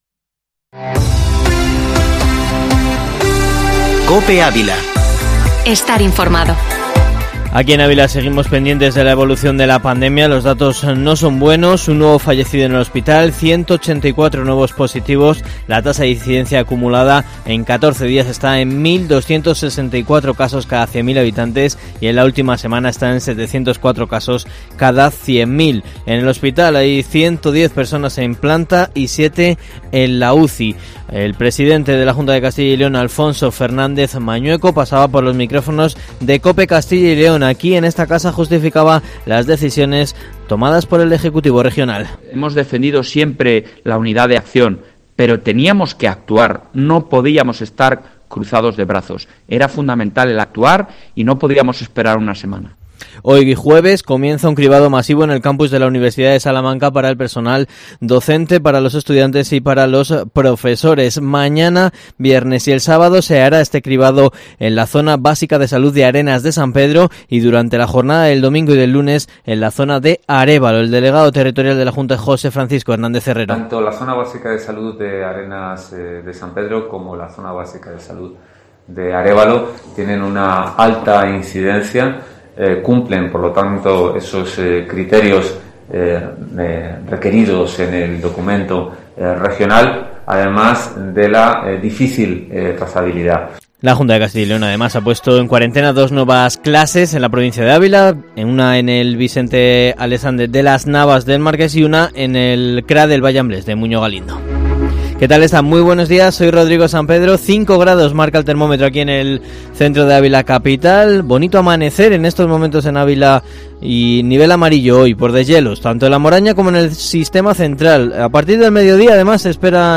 Informativo matinal Herrea en COPE Ávila 21/01/2021